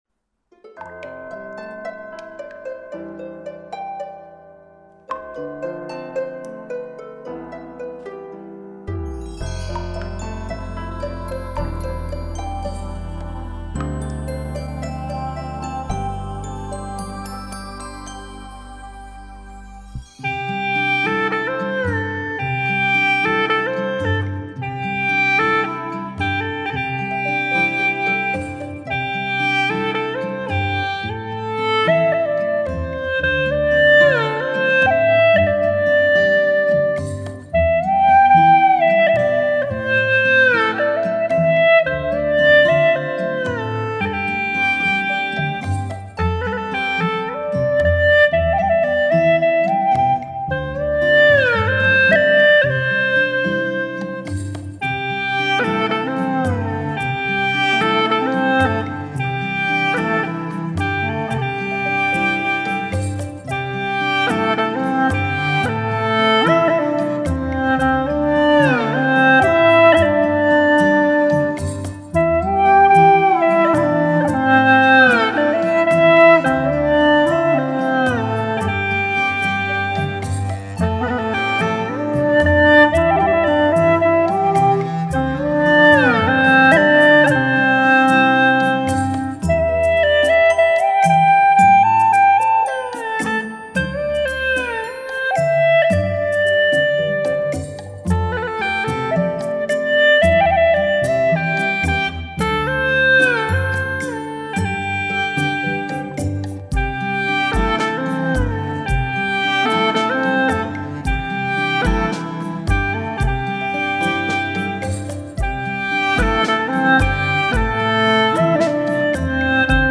调式 : C